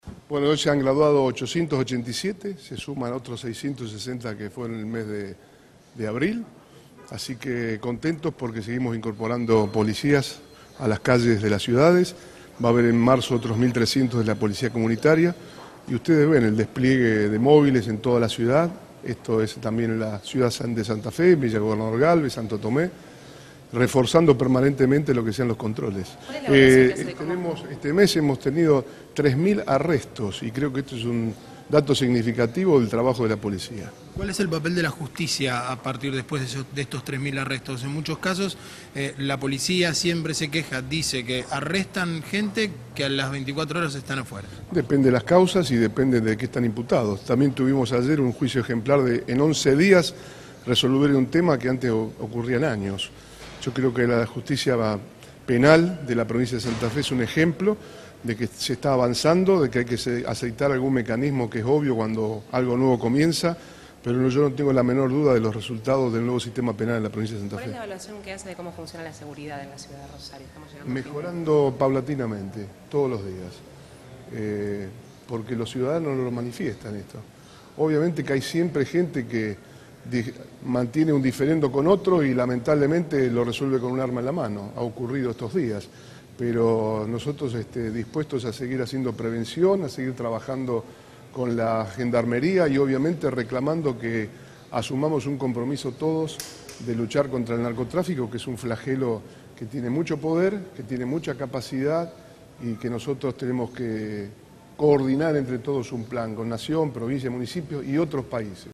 Declaraciones de Bonfatti luego del acto.